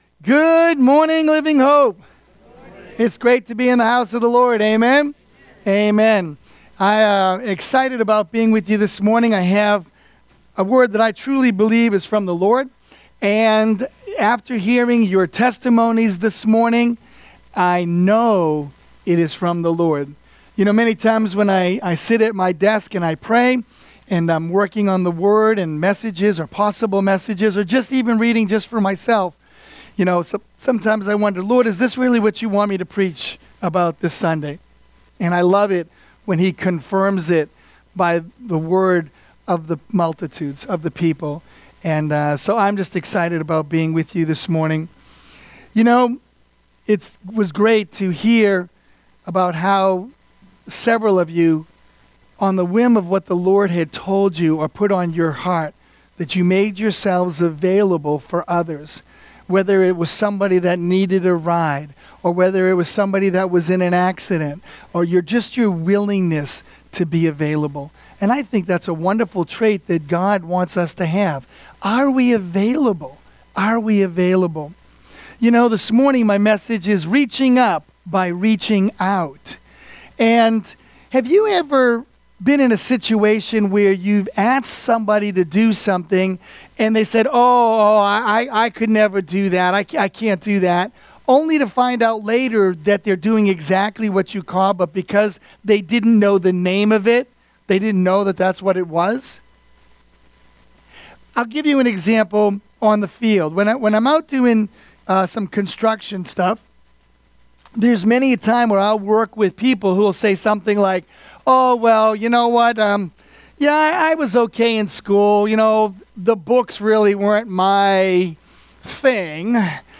Living Hope Sermons